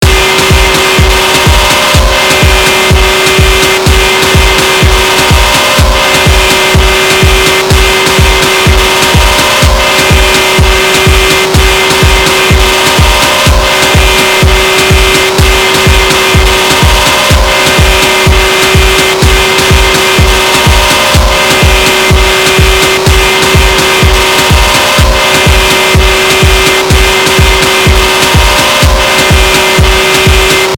Goofy dubstep beat
bounce distortion dub-step effect electronic latino noise rave sound effect free sound royalty free Sound Effects